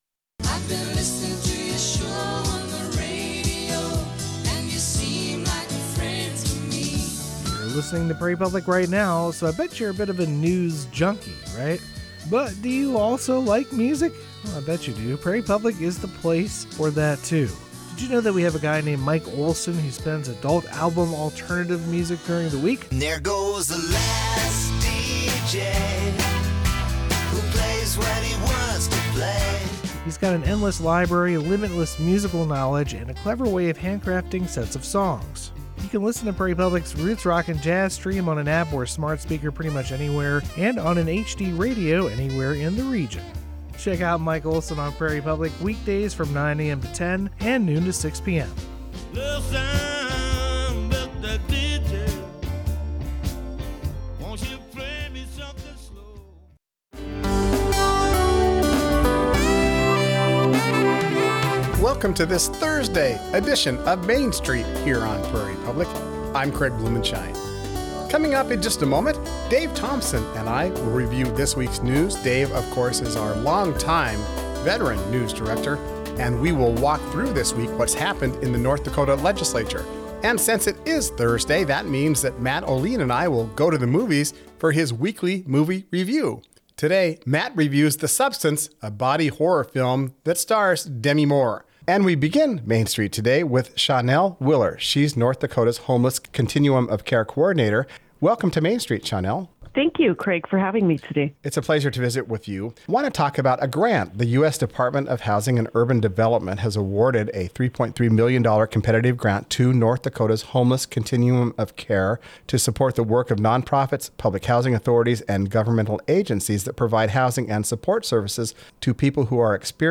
Listen for interviews with authors, artists, and newsmakers that tell the story of our region.